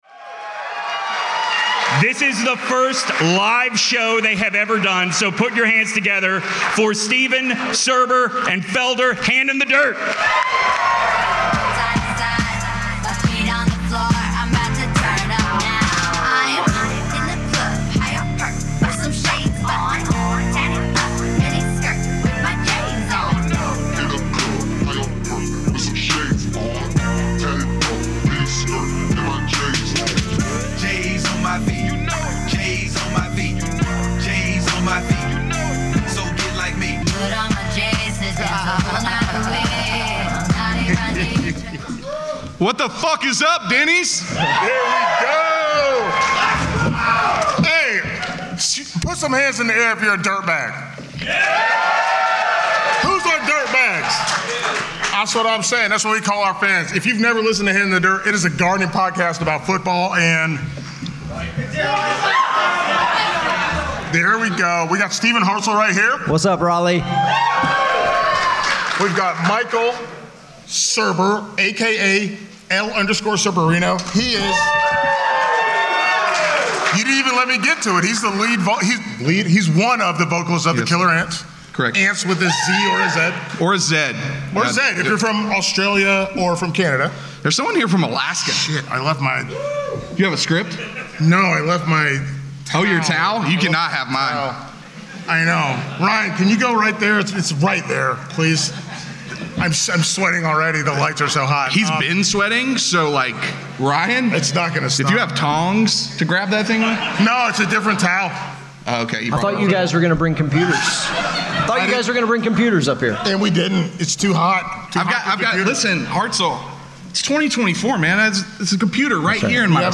A gardening podcast about football, and really it's a lifestyle podcast. LIVE IN RALEIGH NC from the 2024 Sports Podcast Festival.